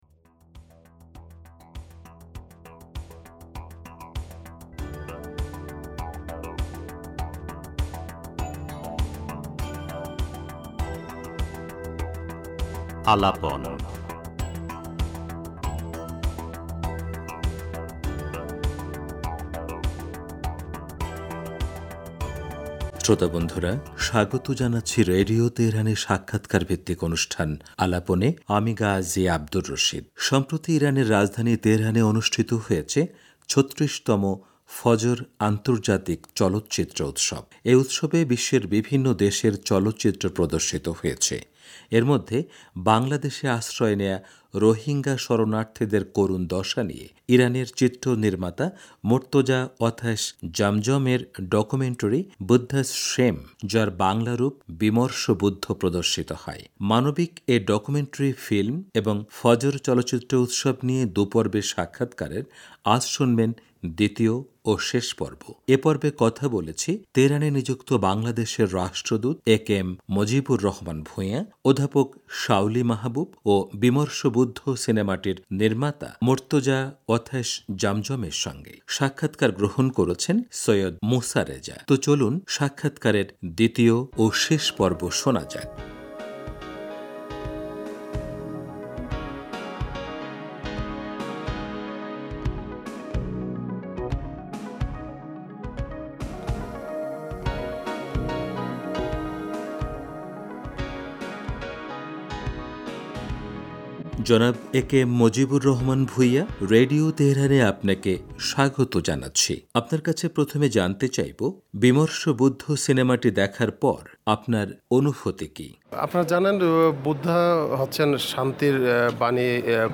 রেডিও তেহরানকে দেয়া সাক্ষাৎকারে তিনি বলেন, মিয়ানমার থেকে রোহিঙ্গা মুসলমানদের যেভাবে নির্যাতন করে বের করে দেয়া হয়েছে তা জাতিগত নিধনের পর্যায়ে পড়ে। বাংলাদেশে আশ্রয় নেয়া রোহিঙ্গাদের সহায়তার জন্য তিনি মানবিকবোধ সম্পন্ন সব ব্যক্তি, সংগঠন ও রাষ্ট্রকে এগিয়ে আসার আহ্বান জানান। সাক্ষাৎকারের দ্বিতীয় পর্বটি তুলে ধরা হলো।